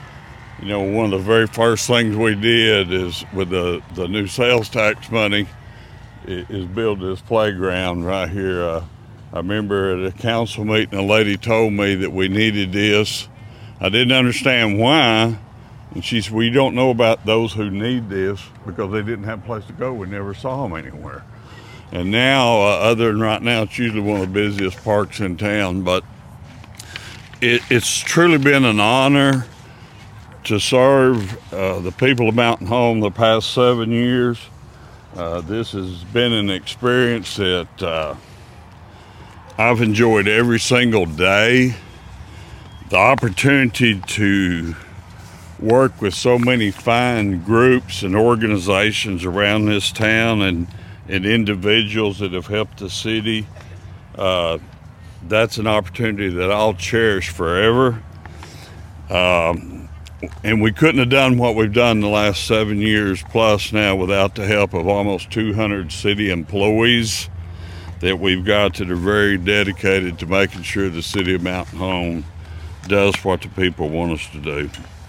In the shadow of the playground the mayor thanked the hard working team and community that has helped him accomplish his agenda in his second term.